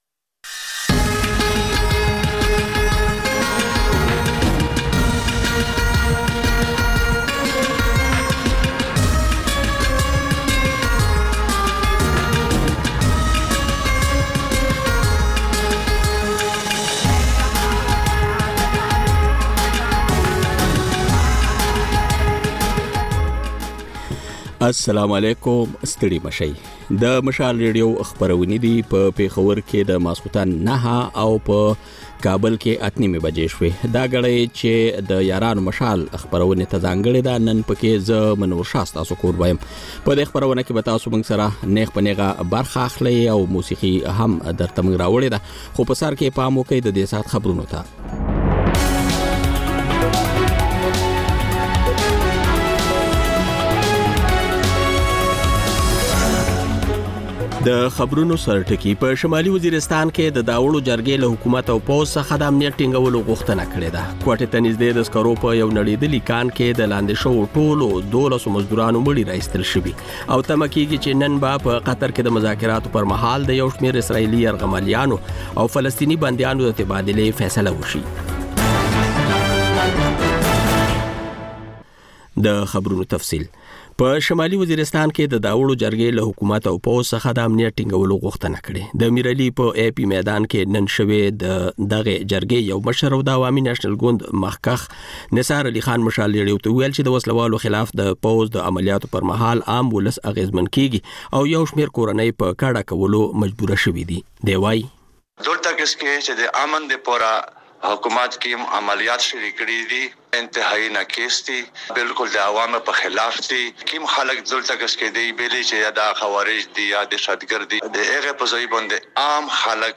د یارانو مشال په ژوندۍ خپرونه کې له اورېدونکو سره بنډار لرو او سندرې خپروو.